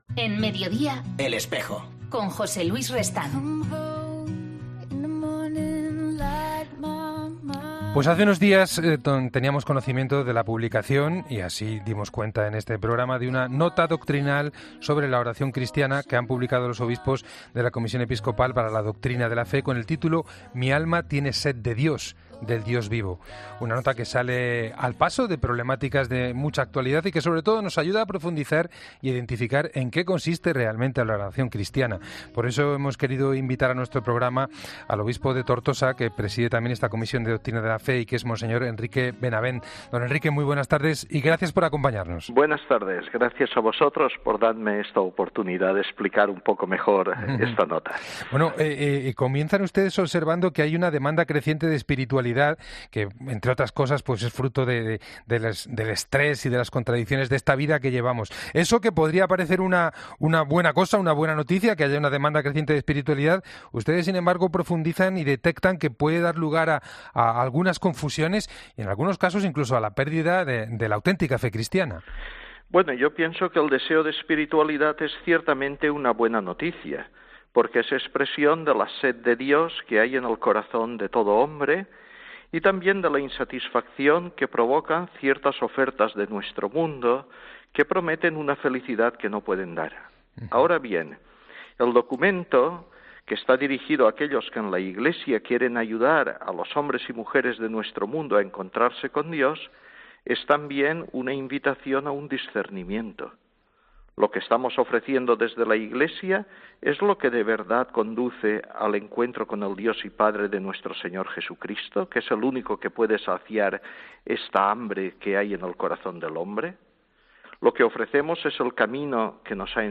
El presidente de la Comisión Episcopal para la Doctrina de la Fe explica el documento en el que alertan sobre elementos procedentes de otras religiones
En 'El Espejo' de la Cadena COPE, Mons. Enrique Benavent, presidente de esta Comisión Episcopal, ha explicado los pormenores de la nota.